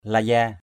/la-za:/